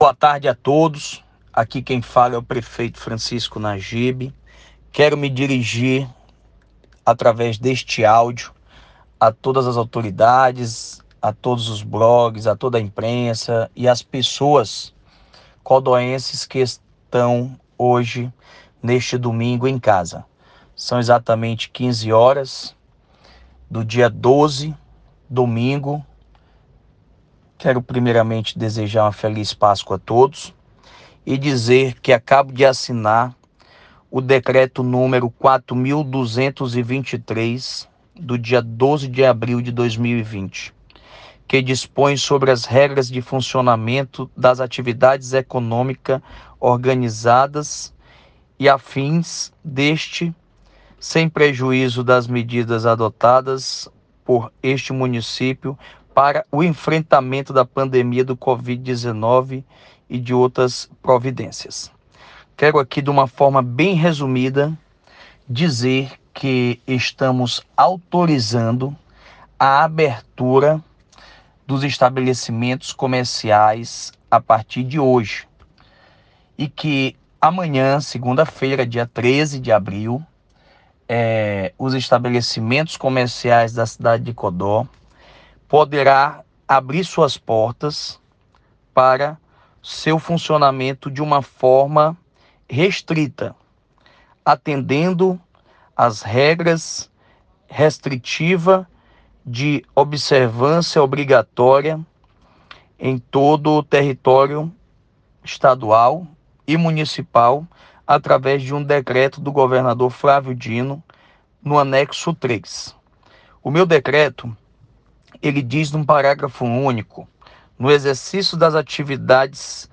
Através de um áudio enviado à imprensa, o próprio prefeito Francisco Nagib explicou como deverá ser o funcionamento dos estabelecimentos comerciais em […]